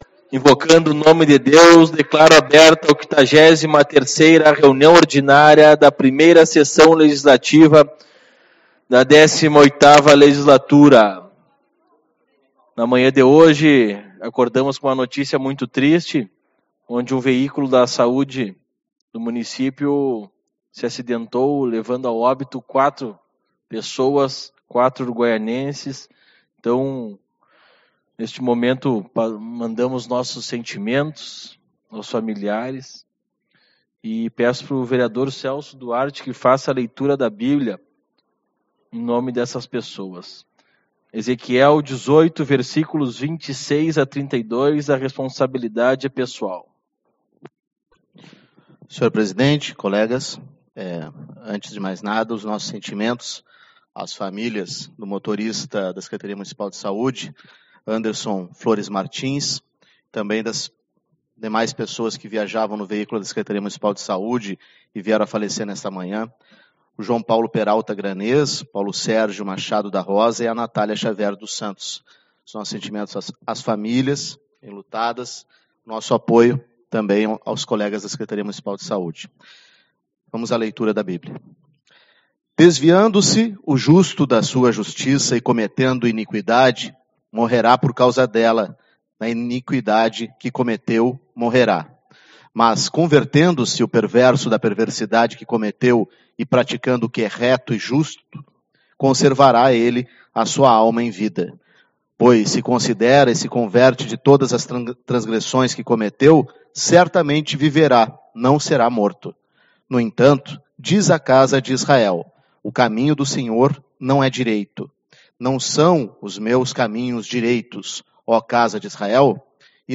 14/12 - Reunião Ordinária